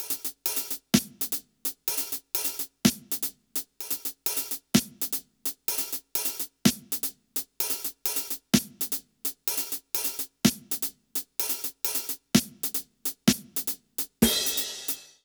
British REGGAE Loop 132BPM (NO KICK) - 3.wav